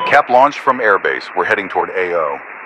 Radio-pilotNewFriendlyAircraft1.ogg